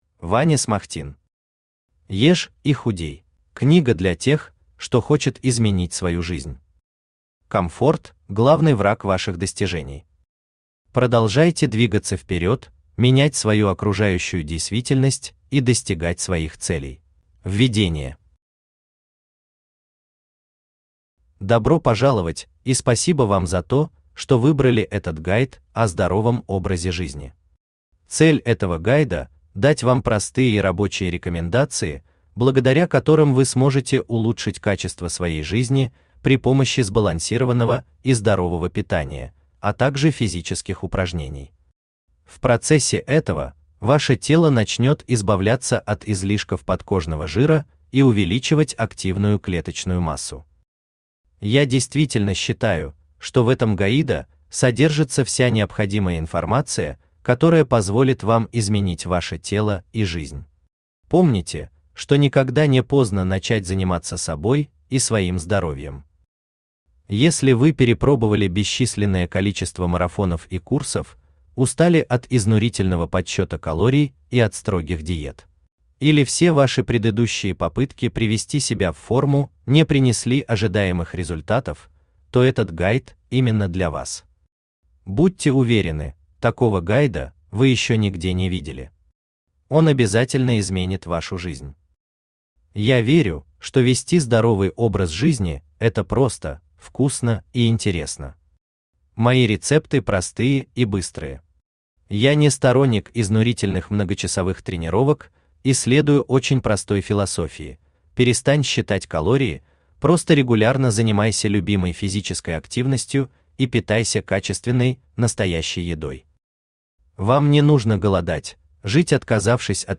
Aудиокнига Ешь и худей Автор Ваня Смахтин Читает аудиокнигу Авточтец ЛитРес.